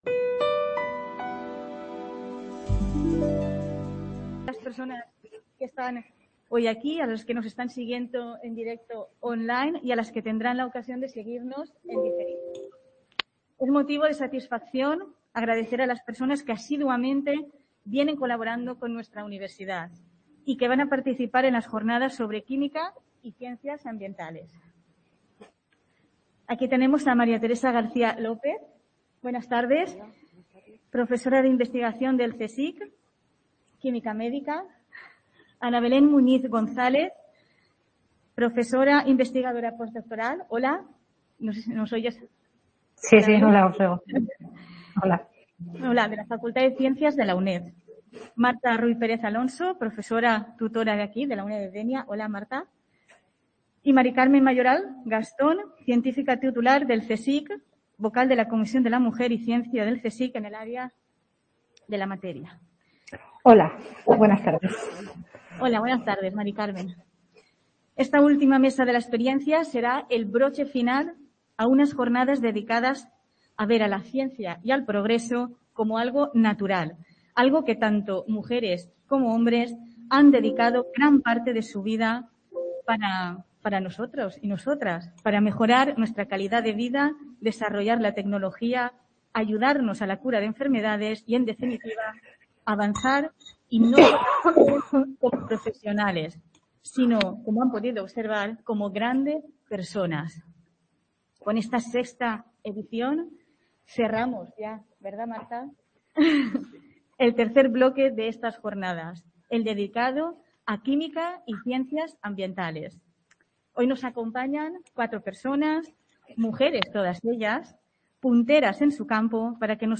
MESA DE LA EXPERIENCIA: Química y Ciencias Ambientales (Varios ponentes)
Mientras que en las Mesas de la experiencia, serán mujeres mayoritariamente, y hombres, cuyo recorrido vital y profesional sea más extenso y puedan aportar su trayectoria profesional como ejemplo de superación, así como también las dificultades con las que han tenido que encontrarse.